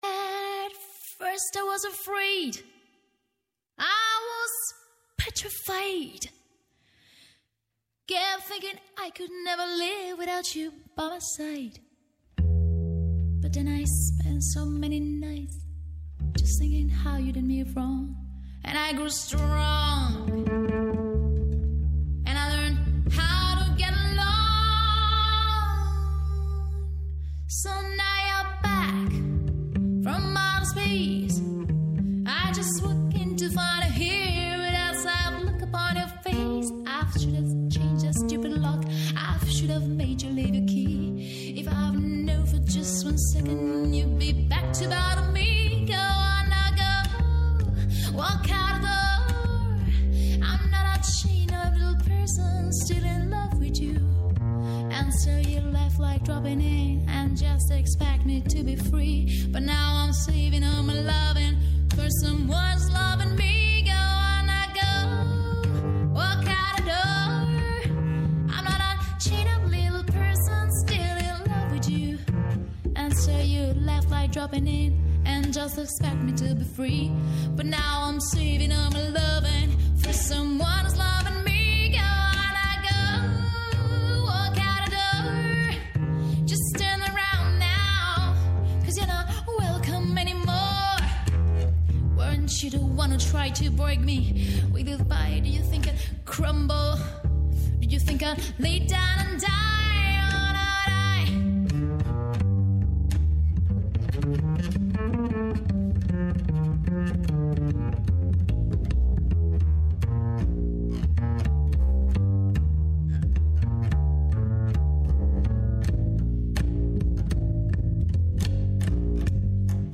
Κάθε Παρασκευή, στις 5 το απόγευμα και για ένα δίωρο, η εκπομπή «Γλυκιά ζωή» προβάλλει τα μοναδικά ελληνικά προϊόντα, τις ευεργετικές ιδιότητές τους και τις μορφές εναλλακτικού τουρισμού της Ελλάδας. Με έμφαση στη γαστρονομία, τον τουρισμό και τη διατροφή, «στρώνει το τραπέζι» με προσκεκλημένους αγρότες και παραγωγούς, διατροφολόγους και διοργανωτές εκθέσεων, σεφ και ξεναγούς.